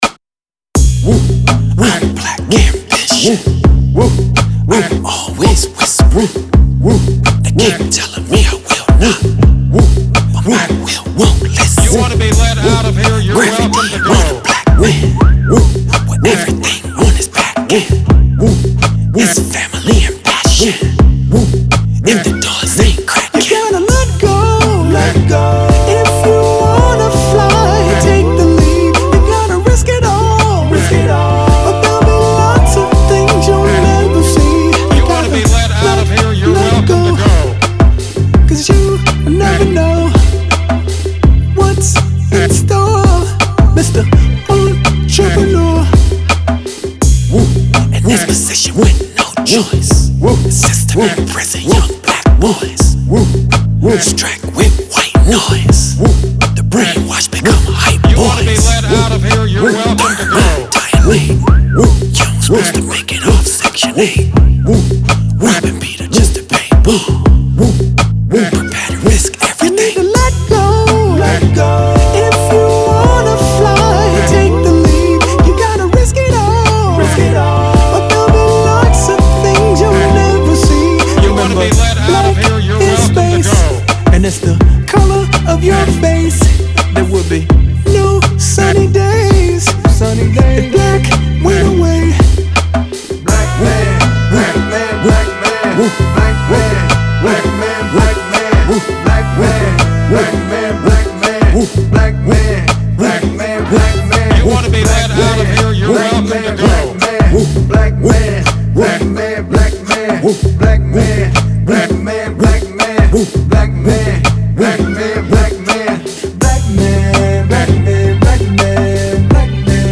it takes a triumphant tone
breezy falsetto floating over a warm beat